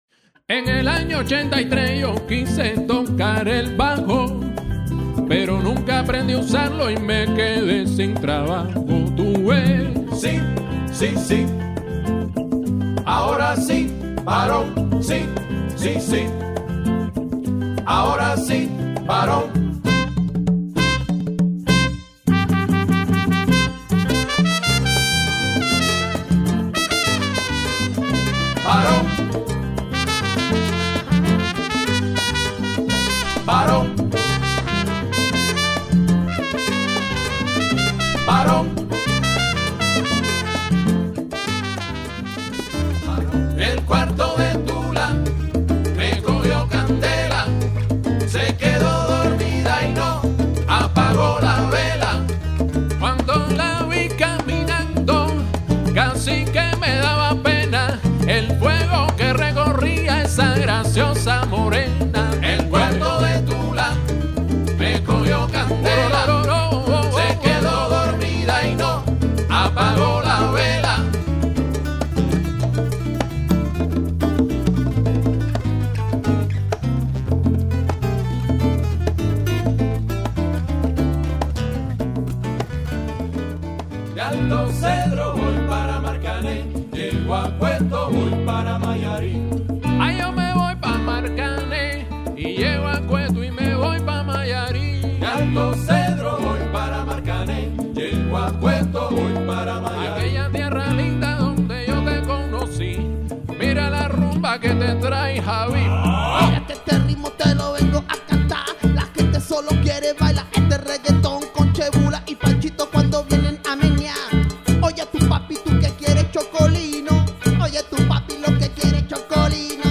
Kolumbijsko-kubansko-slovenska zasedba